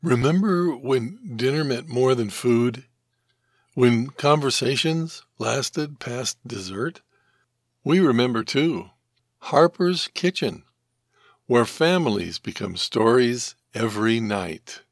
Commercial Restaurant
My delivery is sincere, friendly, believable, and natural—the qualities modern projects demand.